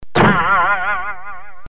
boing_f1YRp34O.mp3